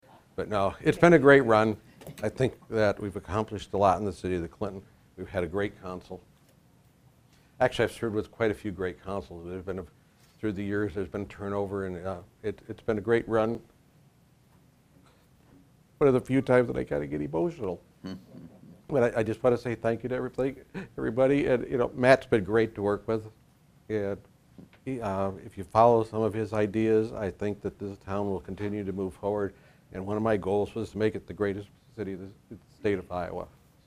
Three Clinton City Council members and the Mayor who were not re-elected in November were honored at the final meeting of the year.